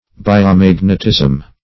Search Result for " biomagnetism" : The Collaborative International Dictionary of English v.0.48: Biomagnetism \Bi`o*mag"net*ism\, n. [Gr. bi`os life + E. magnetism.] 1.